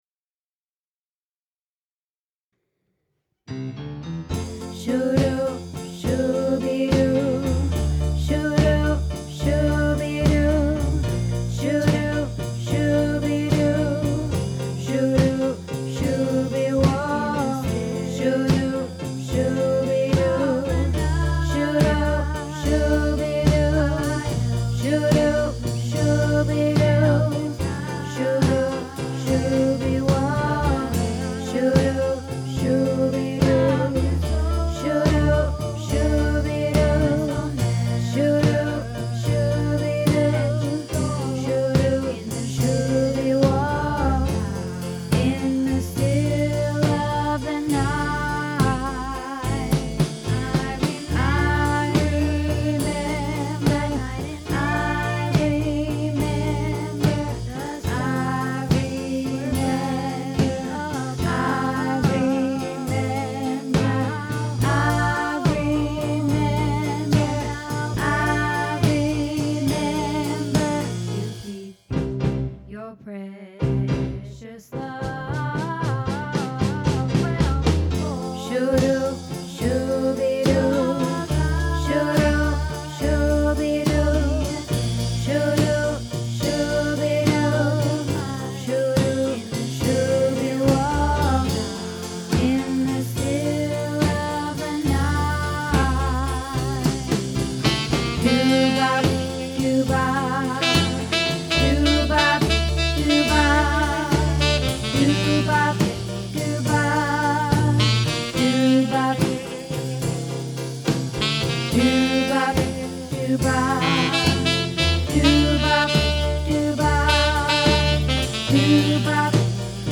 In the Still of the Night - Tenor